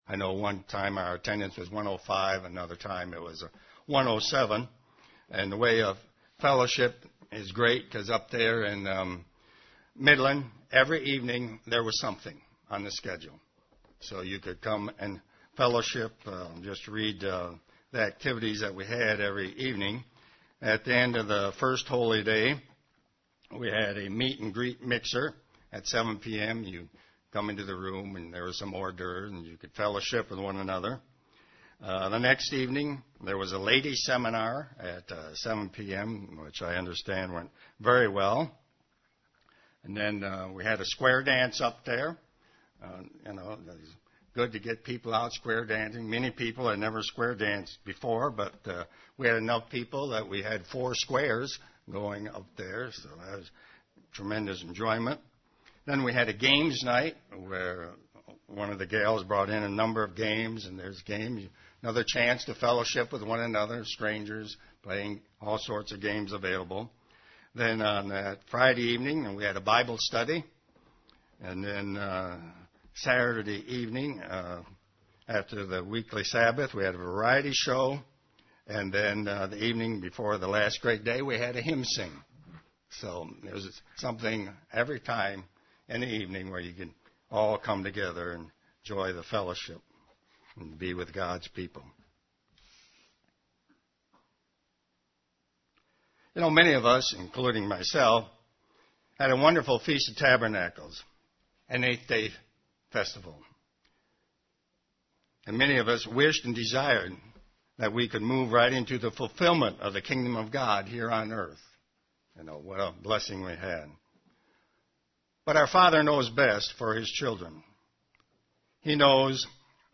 Given in Ann Arbor, MI
UCG Sermon Studying the bible?